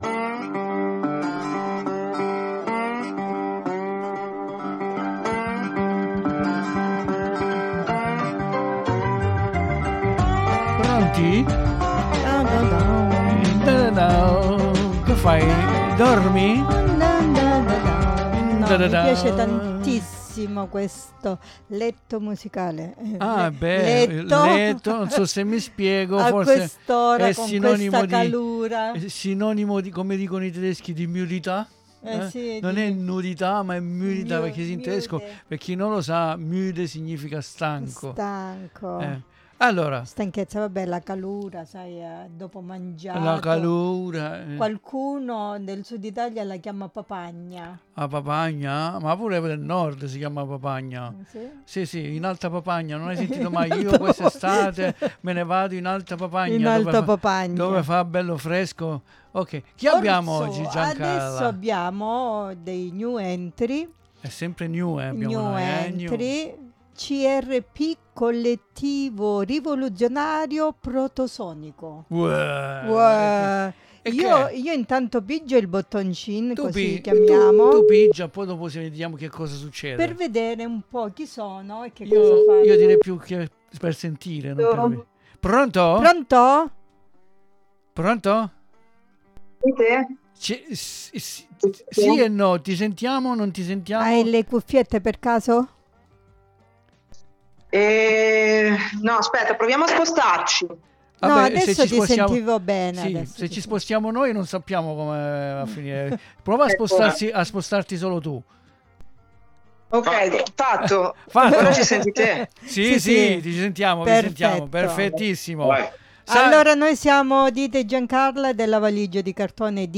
ABBIAMO CHIAMATO PER CAPIRE CHI SONO E CHE MUSICA FANNO!